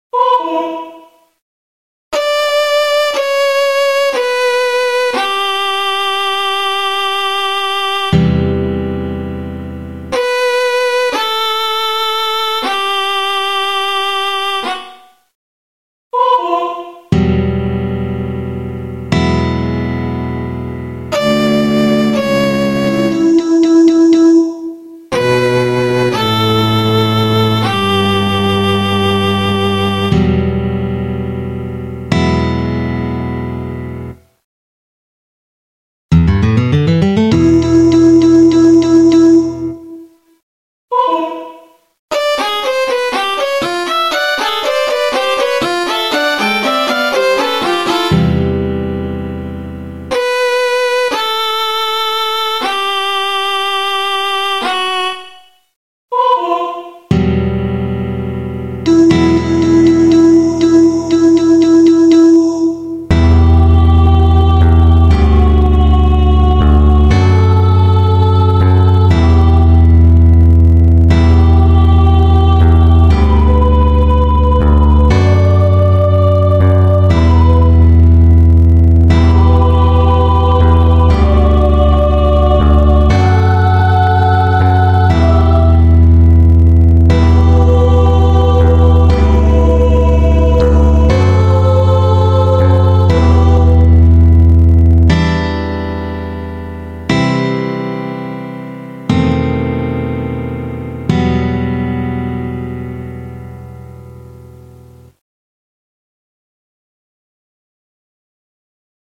The music is scored for a 16 piece orchestra and at times has 9 individual characters singing together on stage.
Please excuse the vocals on “The Bright Life” tunes being computerized ohs and ahs.
The Bright Life 1-10 Death Song Musical Theater